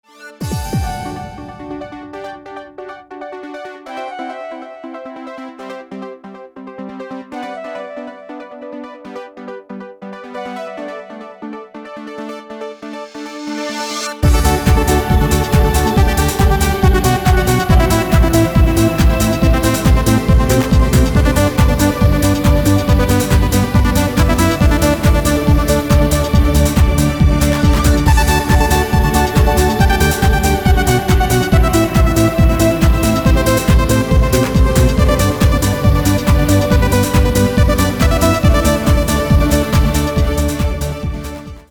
громкие
dance
Electronic
электронная музыка
без слов
Trance
Приятная транс-музыка.